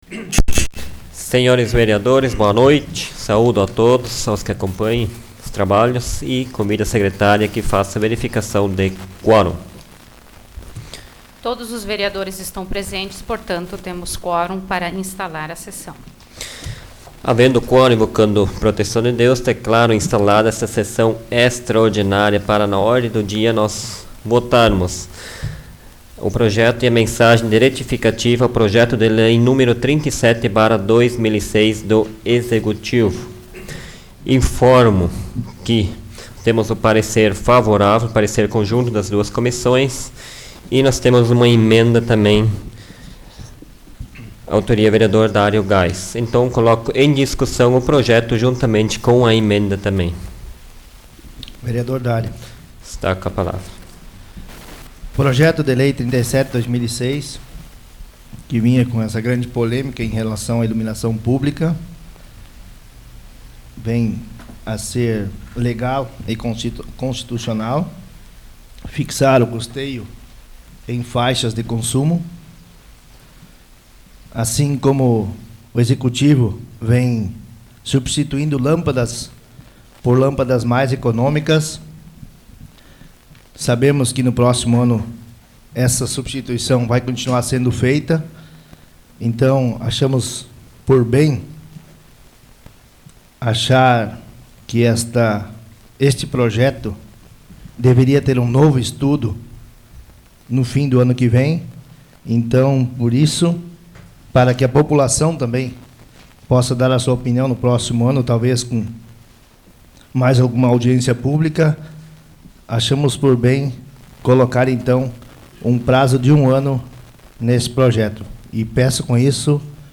Áudio da 30ª Sessão Plenária Extraordinária da 12ª Legislatura, de 28 de dezembro de 2006